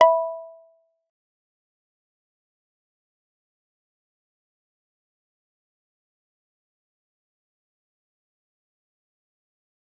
G_Kalimba-E5-f.wav